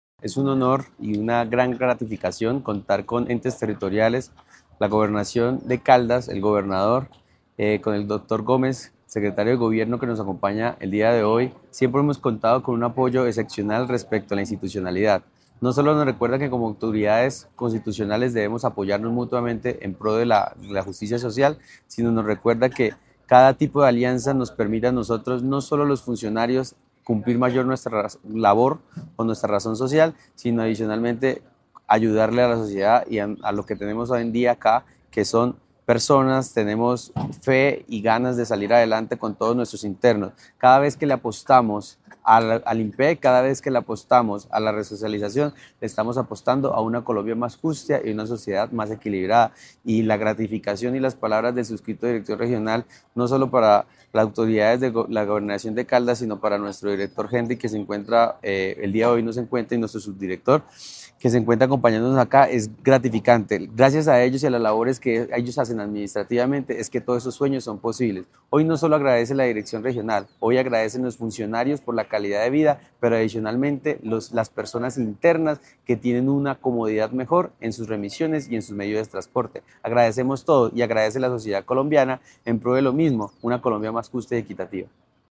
Descargar Video Descargar Audio Michael Anderson Botello, director Regional Viejo Caldas del INPEC. 0
art16194-Michael-Anderson-Botello-director-Regional-Viejo-Caldas-del-INPEC.mp3